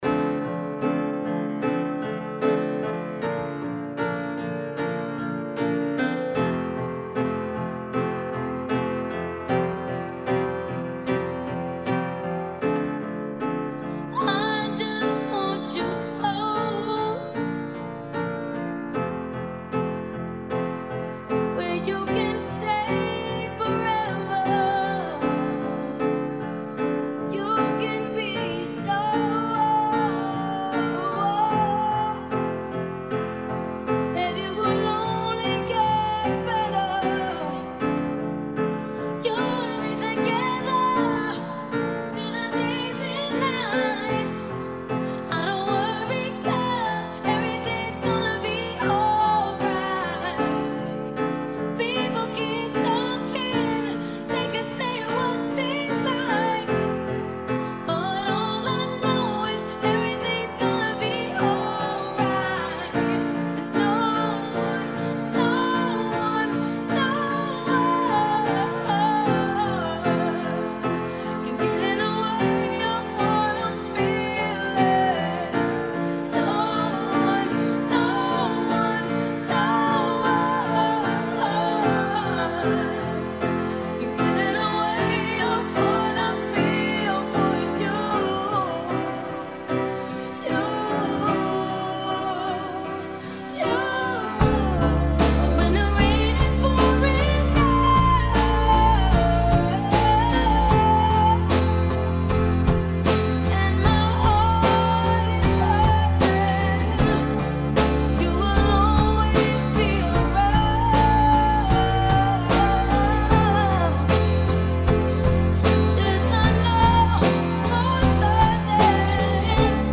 Acapella
Instrumentale